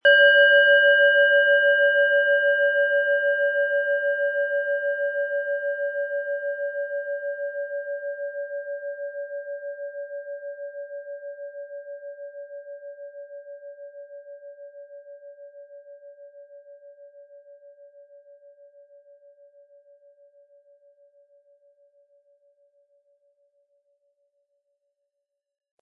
Planetenschale® Im Vertrauen sein & Nachdenken und Verstehen können mit Merkur, Ø 12,3 cm, 180-260 Gramm inkl. Klöppel
Planetenton 1
Lieferung inklusive passendem Klöppel, der gut zur Planetenschale passt und diese schön und wohlklingend ertönen lässt.